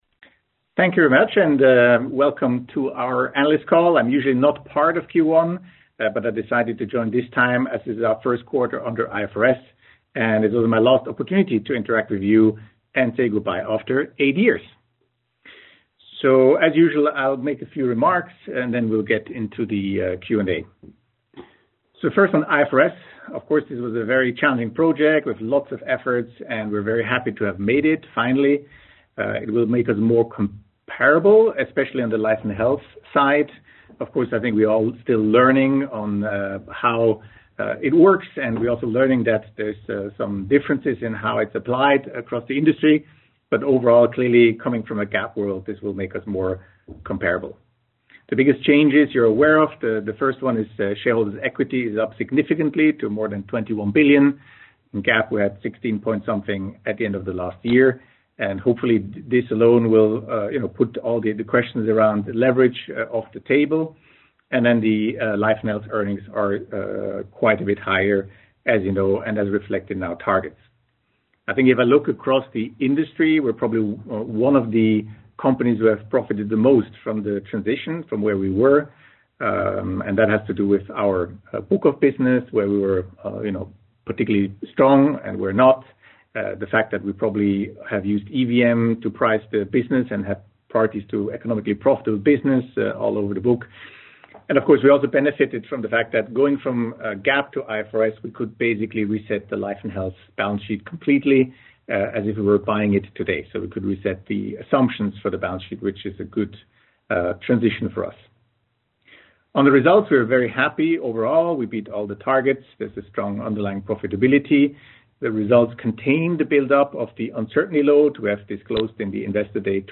q1-2024-call-recording.mp3